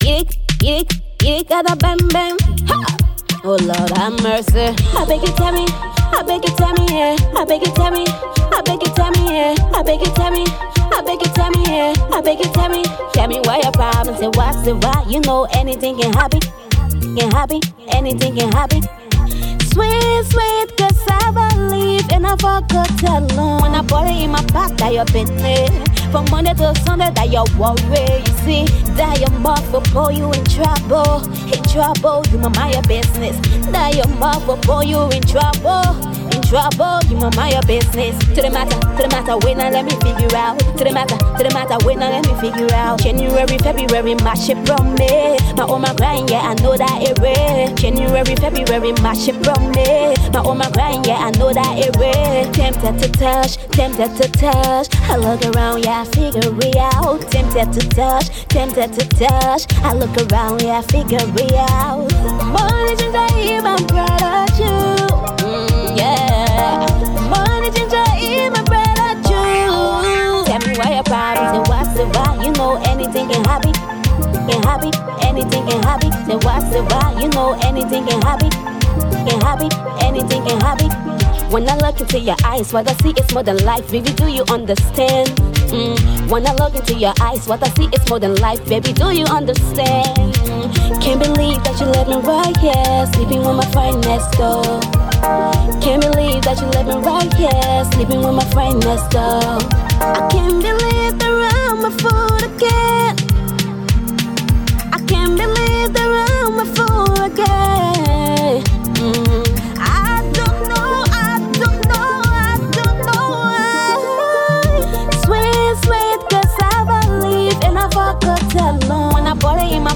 dancehall beat